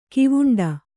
♪ kivuṇḍa